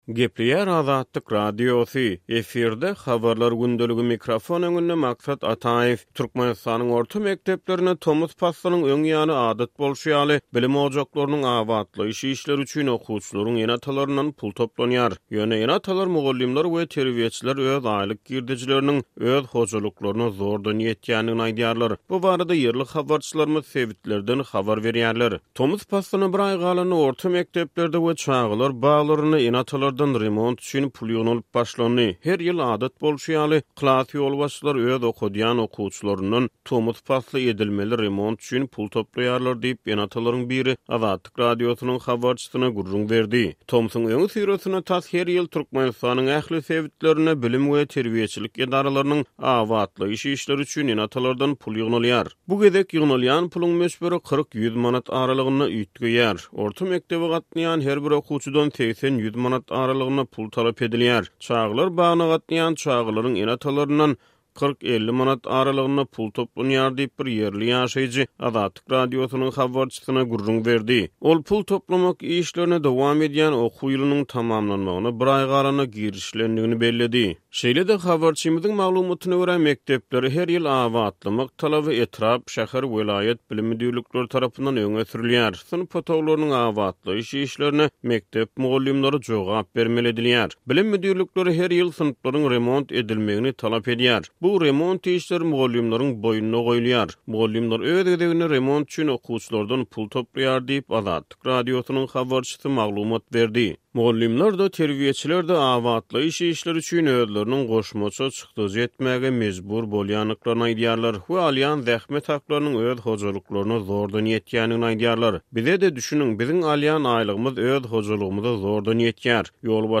Bu barada ýerli habarçylarymyz sebitlerden habar berýärler.